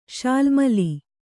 ♪ śalmali